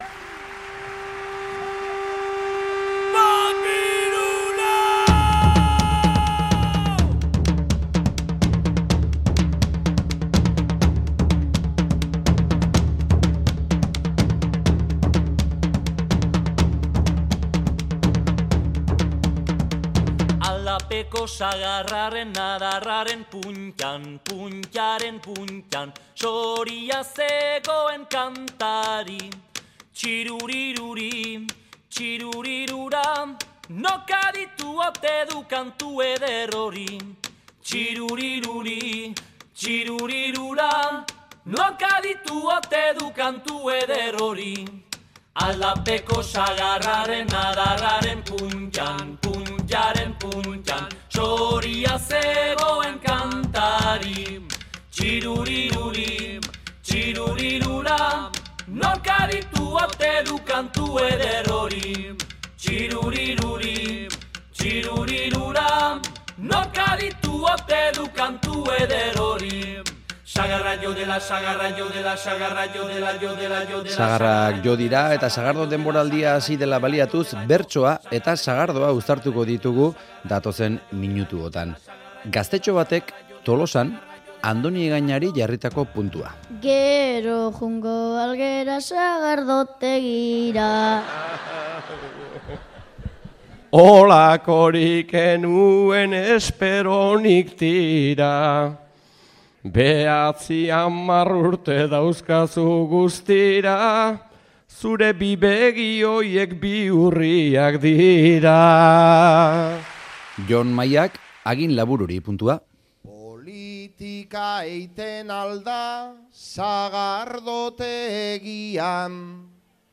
Bertsolari askori jarri izan zaizkie sagardoari buruzko gaiak eta puntuak, kupel artean botatakoak, Adan eta eva sagardotegian...